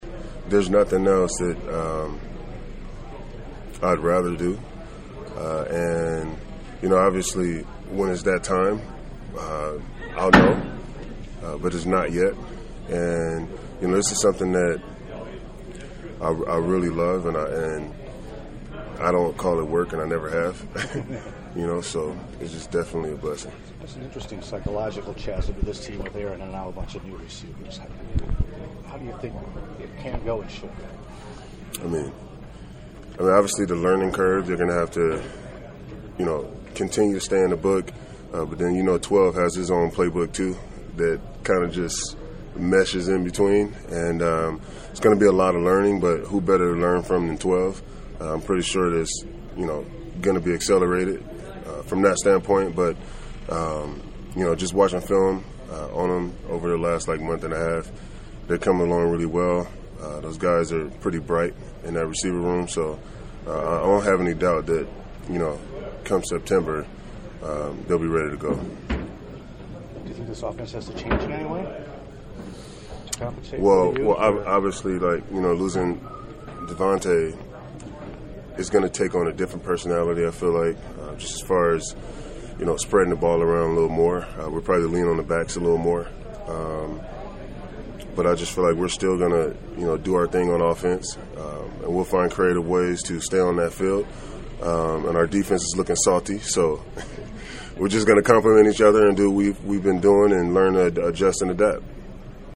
It was great to be able to mosey up to the Big Dog’s locker and chat for the first time two years.     I asked him about how the dynamic is shifting with Rodgers aging and Adams in Silver and Black but I started by acknowledging retirement is something that’s not on his radar, yet.